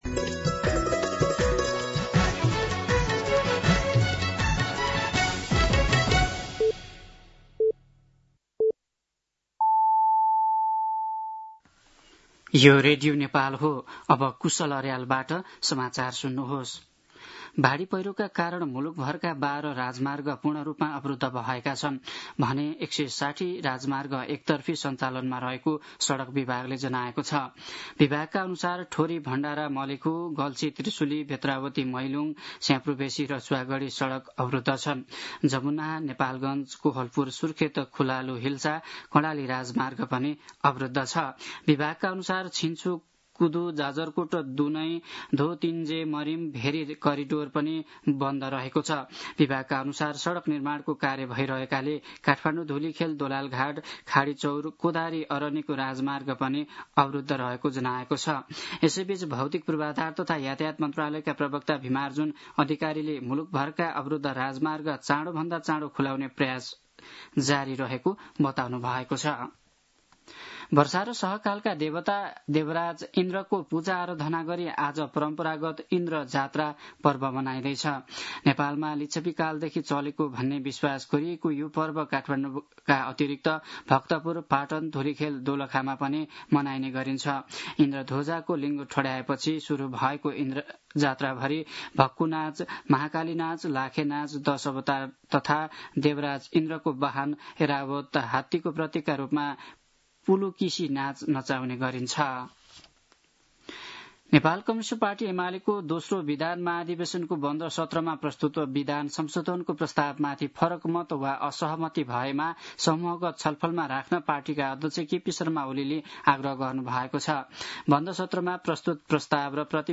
दिउँसो ४ बजेको नेपाली समाचार : २१ भदौ , २०८२
4-pm-News-1.mp3